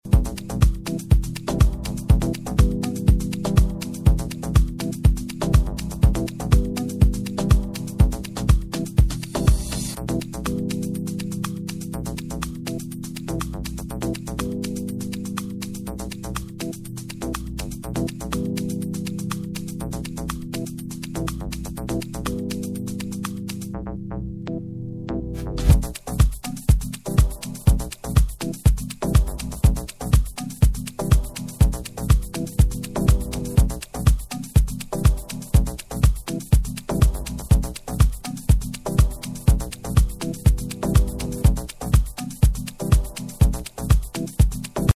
Remastered reissue
Techno